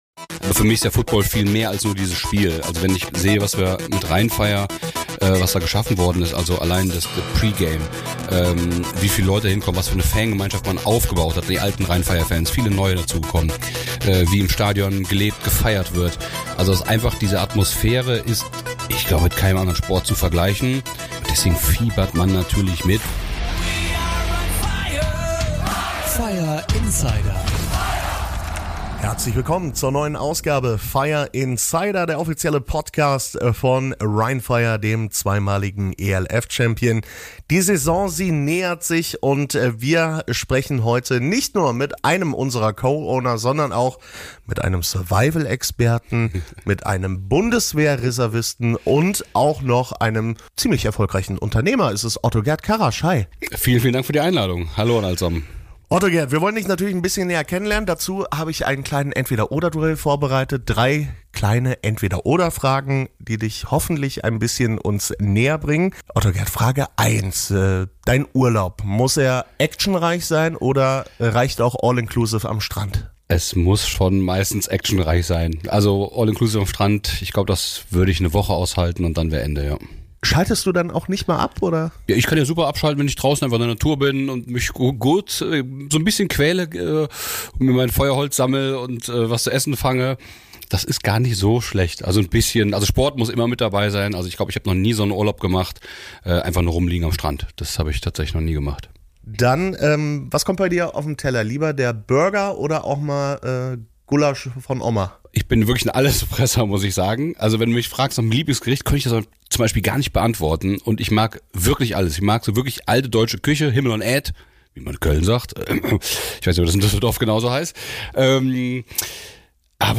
Außerdem gibt’s ein exklusives Interview mit NFL-Veteran Brian Hoyer, dem langjährigen Backup von Tom Brady, der am 6. April unser Training besucht hat. In der Redzone blicken wir auf die große Saisoneröffnung am 27.04. um 11:30 Uhr auf dem Averdunkplatz in Duisburg.